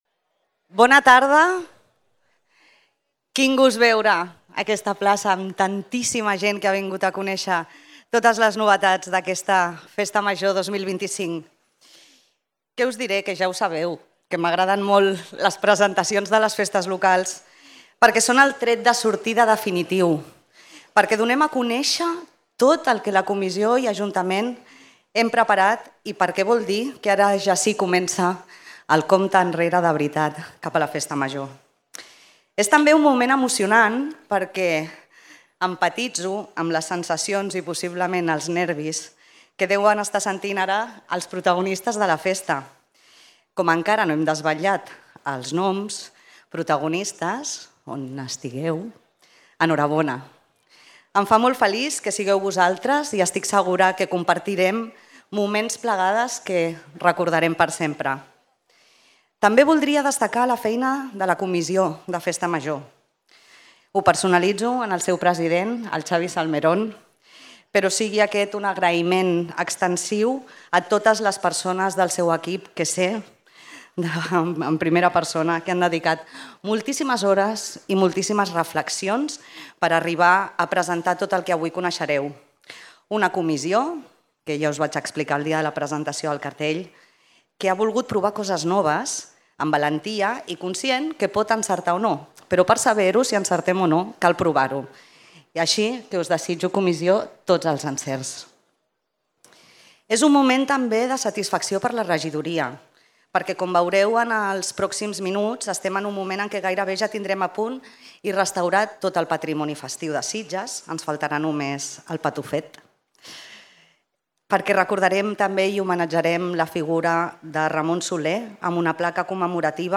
El baluard Vidal Quadres, rebatejat com ‘La plaça’ perquè la comissió el contempla com un nou espai per a fer-hi activitats infantils, juvenils i familiars, fou l’indret escollit per a presentar el programa d’una Festa Major que vol vindicar el so i el soroll. Us oferim l’acte de presentació íntegre.